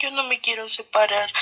Meme Sound Effect